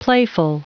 Prononciation du mot playful en anglais (fichier audio)
Prononciation du mot : playful